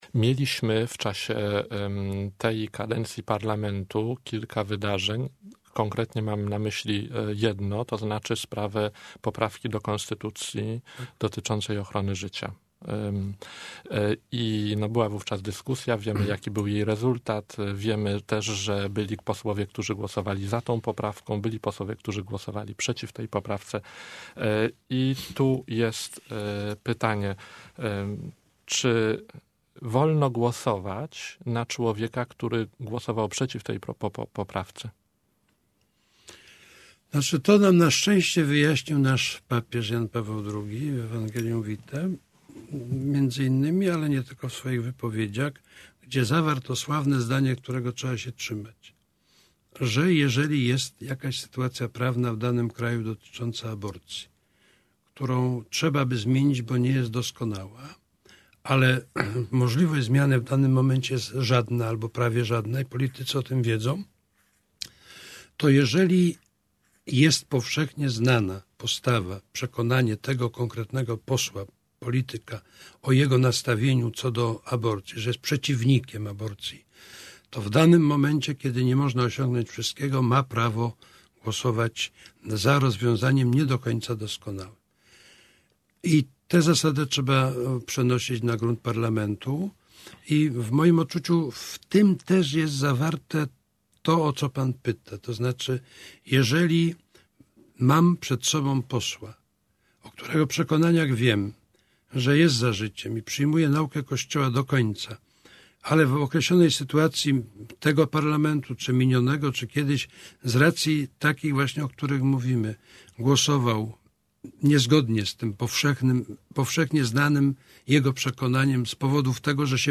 (obraz) | Posłuchaj wywiadu abp. Nyczem 1 (dźwięk) | Posłuchaj wywiadu abp.